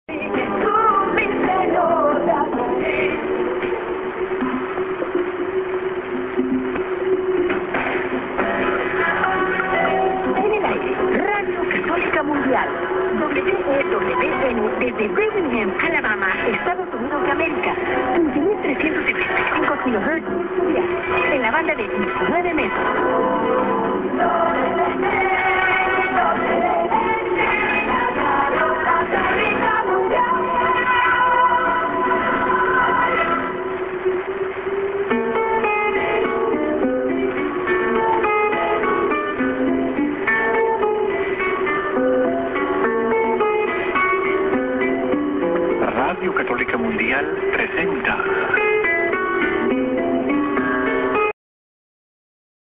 b:　Spanish ID+SKJ(women)->ID(man)->